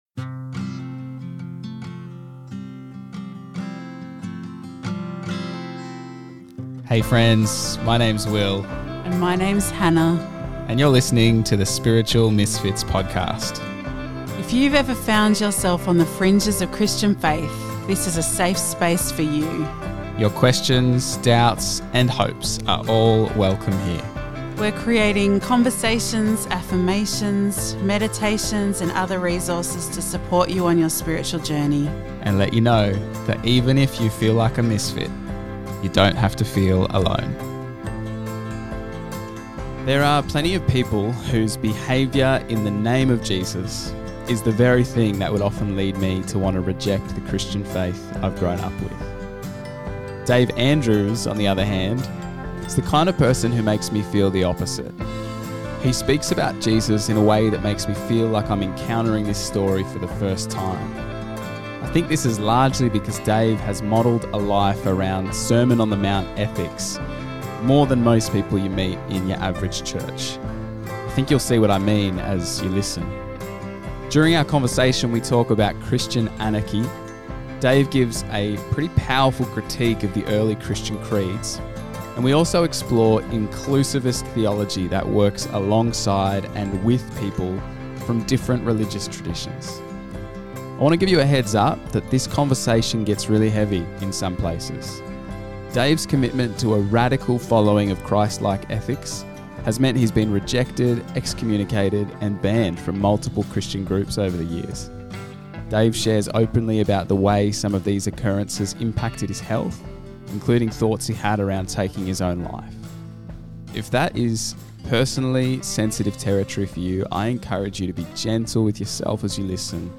An Introductory talk at a book launch about Sebastian Castellio, a brilliant theologian, whose calls for tolerance and dialogue, over against the ethno-religious extremism of the sixteenth century, sparked a violent backlash from his colleague, the Reformer John Calvin, who did his best to destroy him.